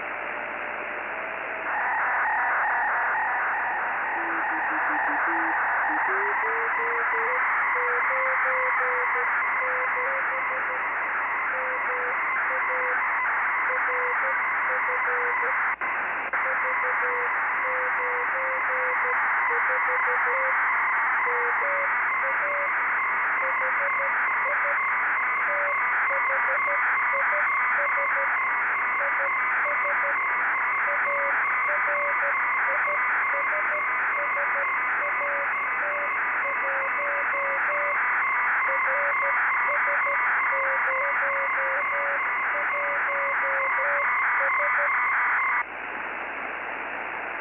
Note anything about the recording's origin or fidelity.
My Doppler correction is not very good, but i can decode...